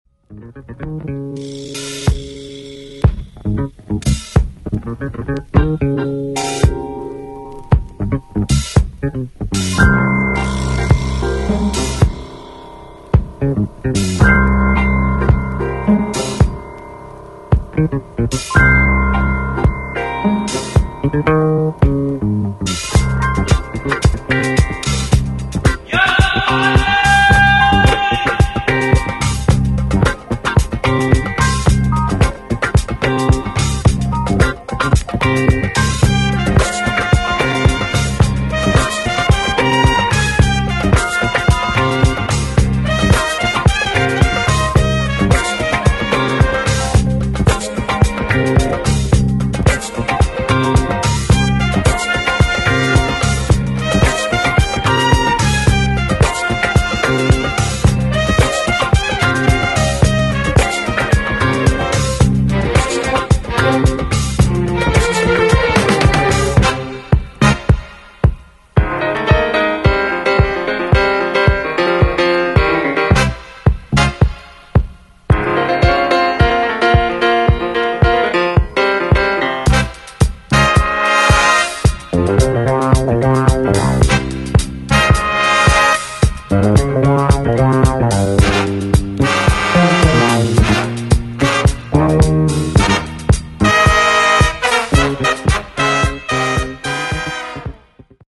danceable obscure and cult material
Turkish female singer